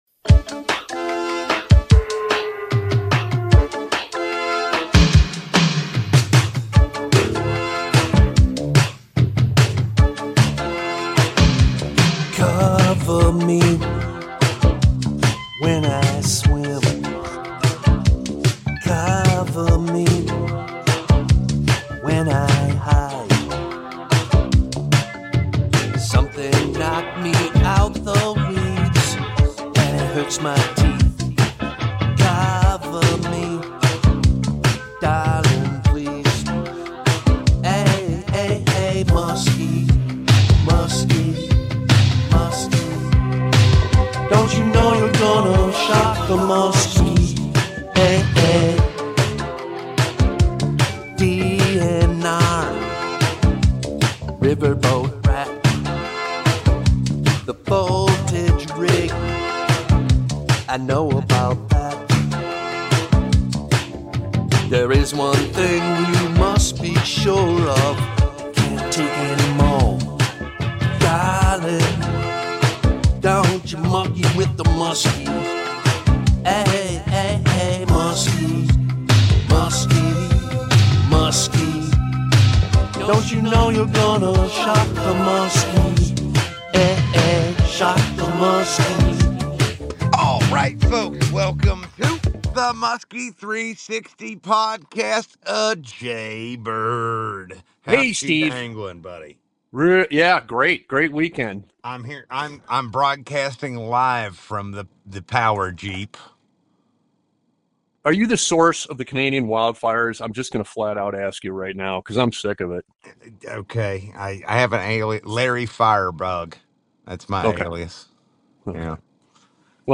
on location from Eagle Lake Ontario. Listener Q&A and more!